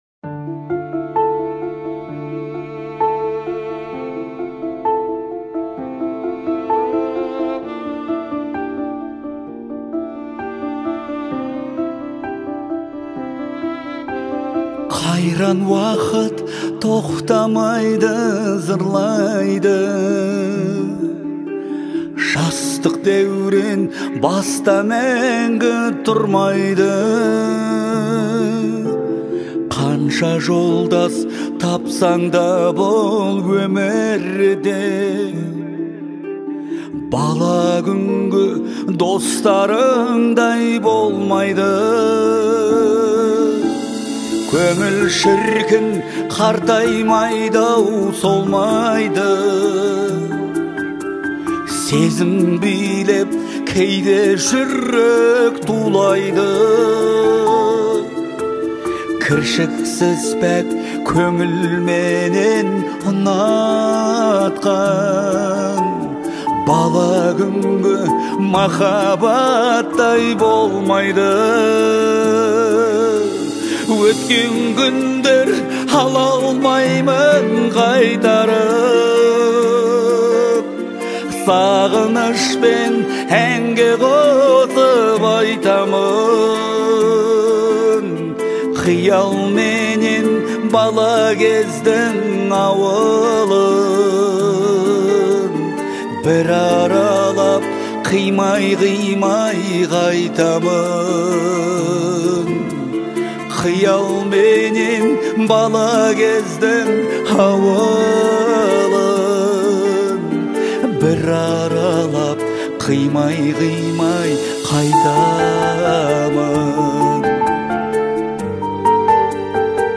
это трогательная песня в жанре казахского поп